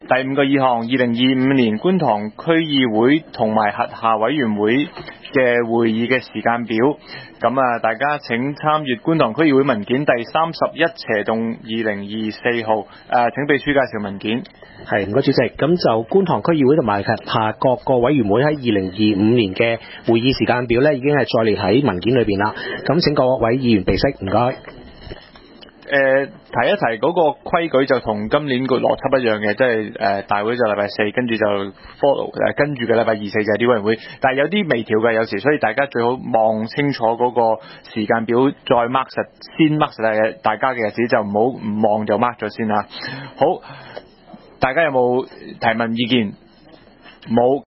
观塘区议会大会的录音记录